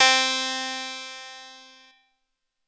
GUnit Stunt101 Harpsichord.wav